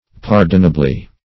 Pardonably \Par"don*a*bly\, adv.